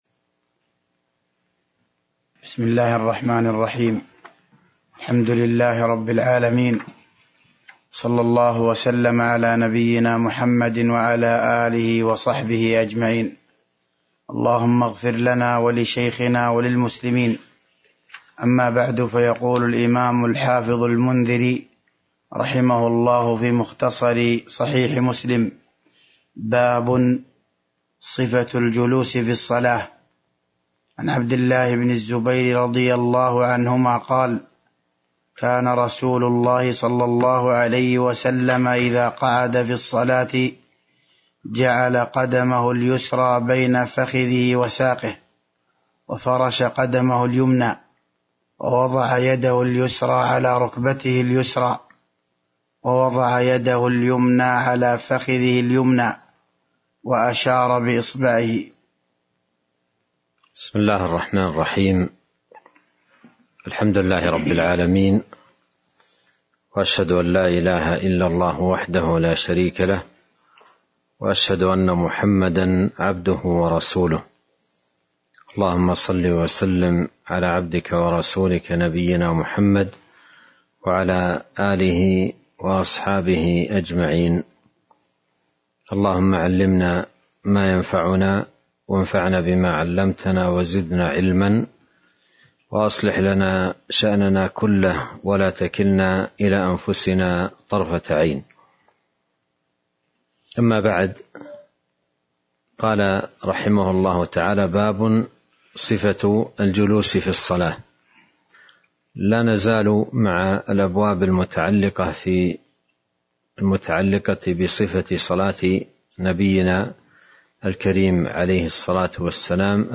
تاريخ النشر ٧ جمادى الأولى ١٤٤٢ هـ المكان: المسجد النبوي الشيخ